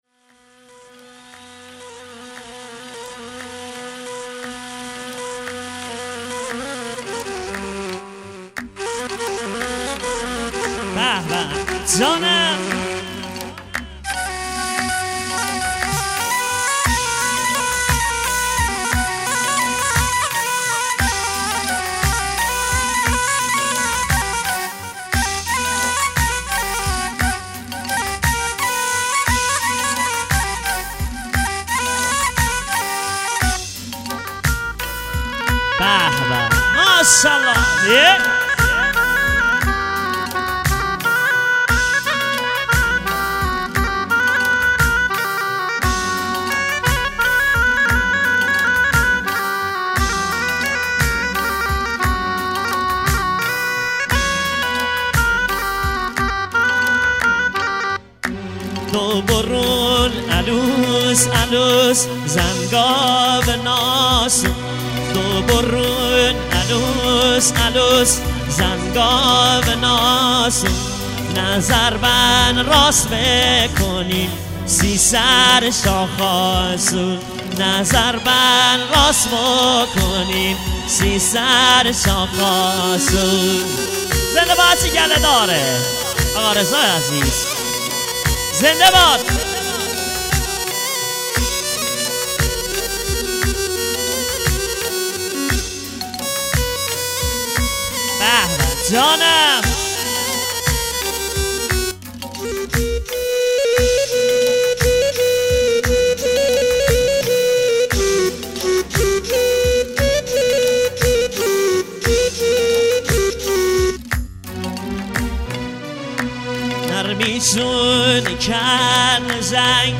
ترانه محلی بختیاری